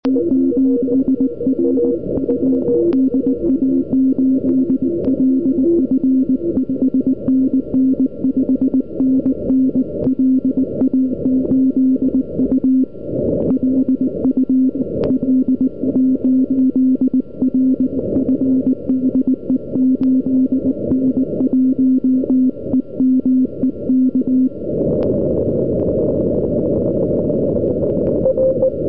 Signál je velmi silný a podle tloušťky bílé čáry je možno mu dát na "oko" report 599
zde si můžete poslechnout jak takový signál při spojení vypadá na ucho. U 8N1EME se jedná o parabolu 32m průměr a 500W. Vyměněné RST 599/599.